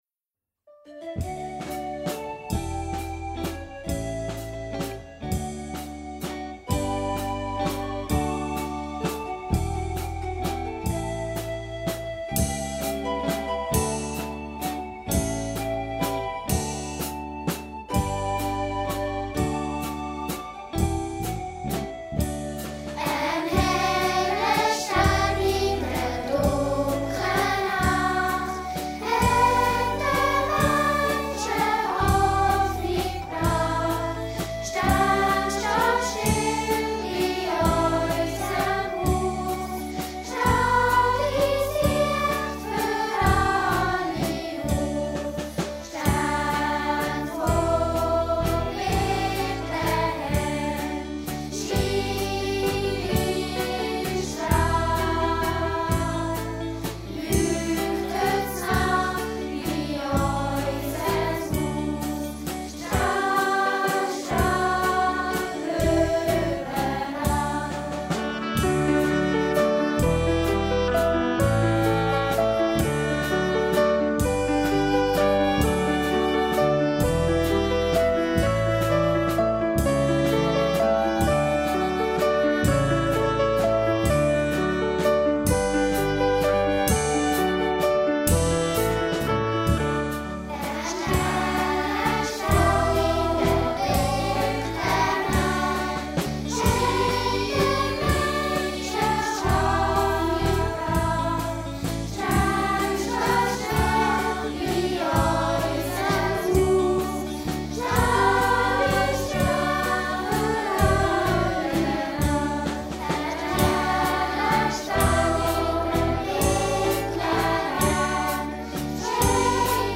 Am 24. Dezember fand die Krippenfeier in Gipf-Oberfrick statt.  Die Lieder dazu wurdenalle vorgänig einzeln aufgenommen uns als Chor zusammen abgemischt.